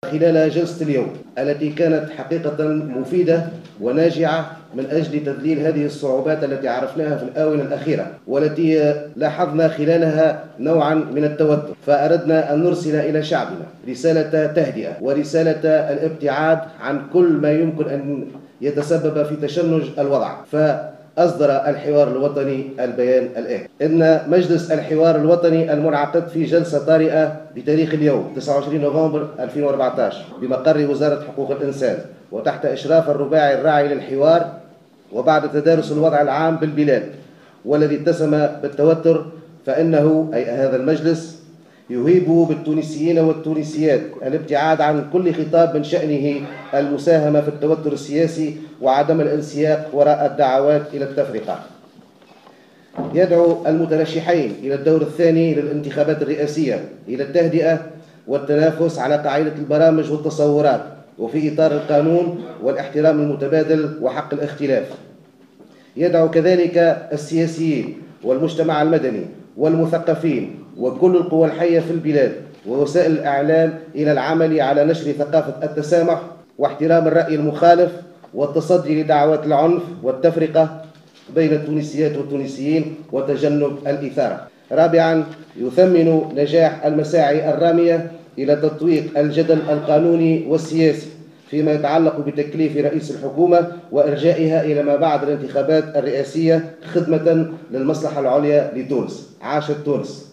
تصريح إعلامي